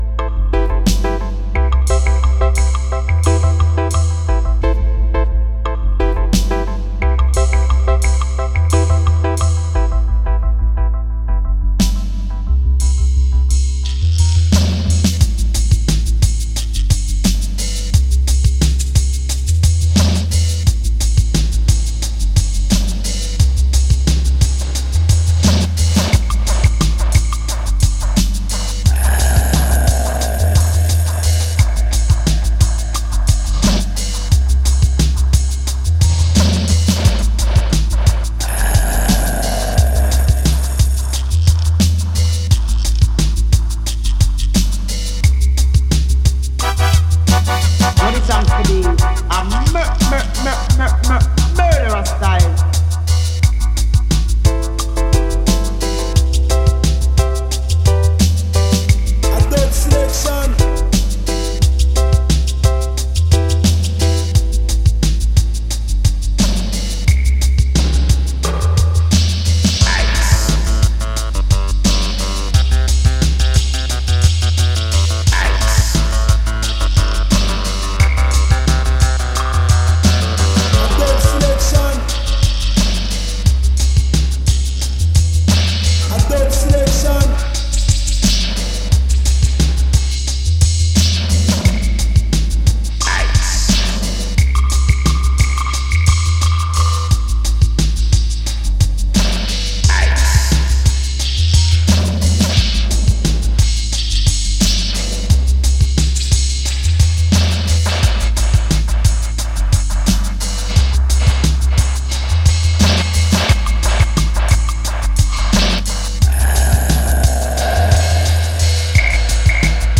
ソリッドでダビーなブレイクビーツ〜ダウンテンポ路線に仕上がっていて全曲それぞれナイス。